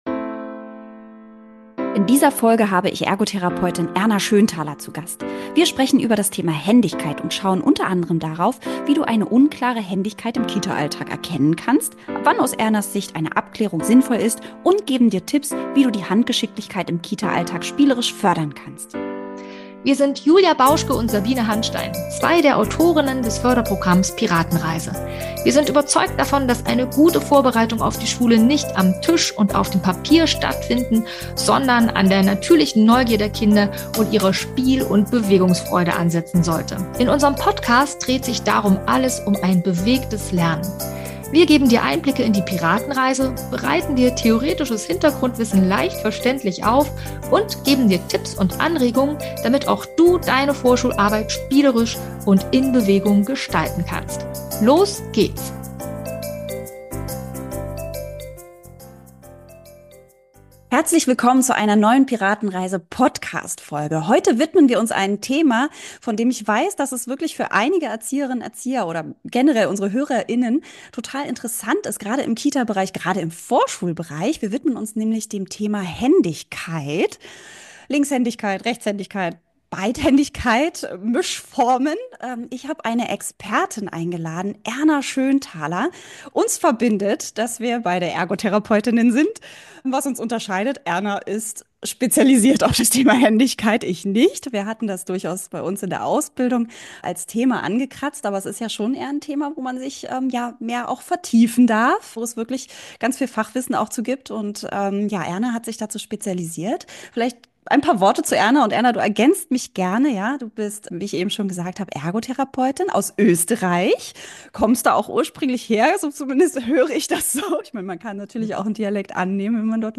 #92 - Expertinnen-Interview: Händigkeit ~ Piratenreise Podcast